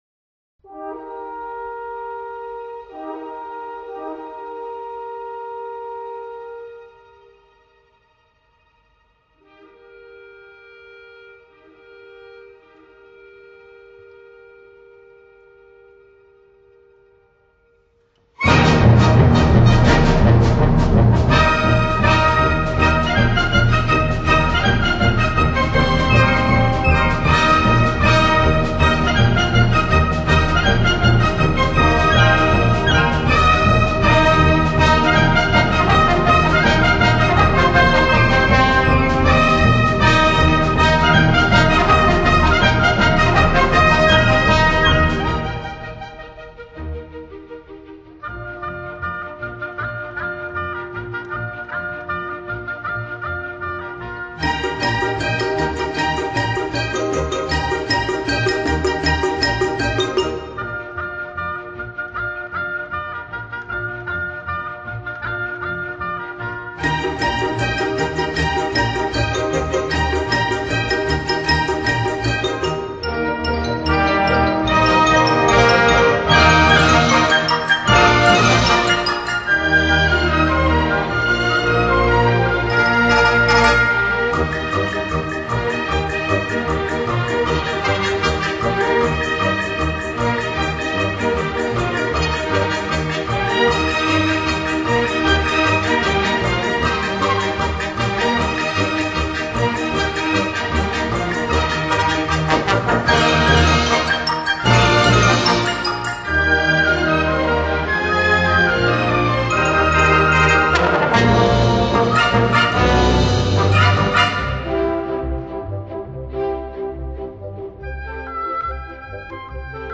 动听绝伦的发烧极品，顶级专业发烧器材的试音精品
音质、解像度、音量感、歪感、透明感五大方面均优于普通CD
低频比XRCD24的表现要好，更加有现场感！